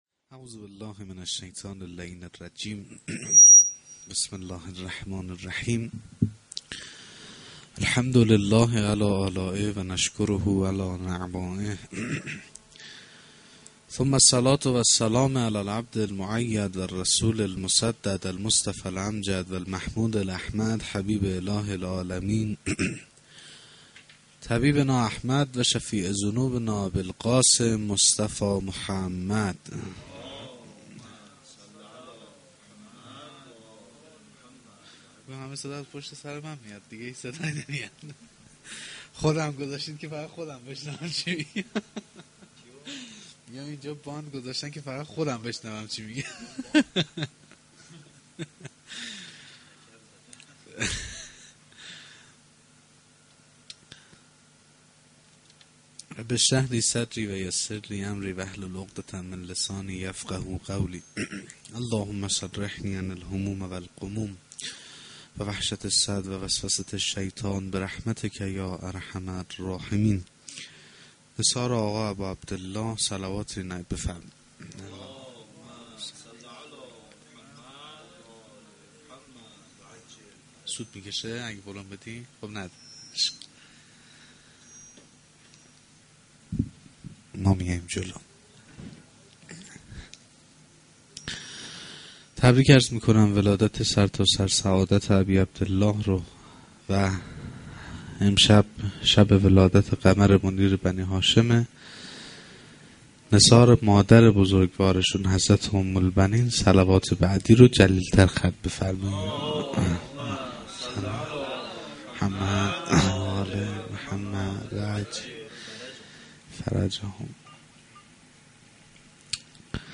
veladate-emam-hosein-a-93-sokhanrani.mp3